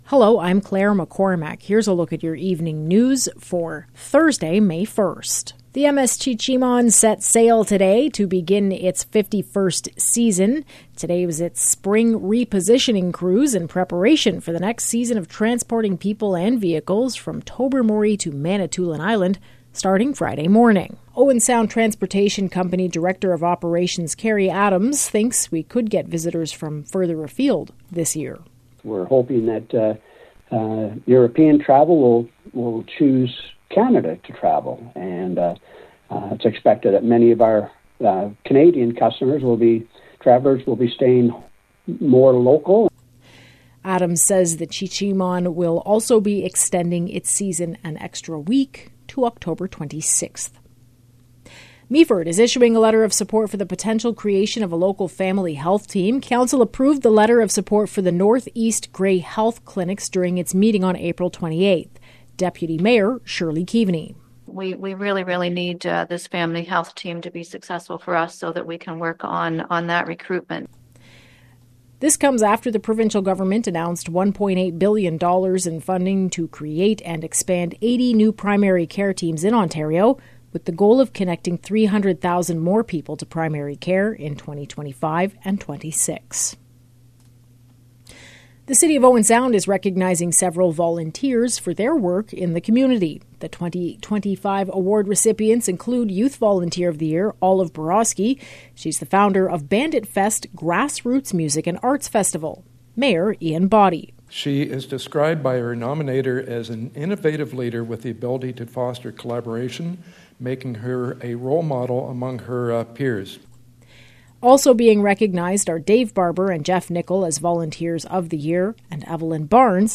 Evening News – Thursday, May 1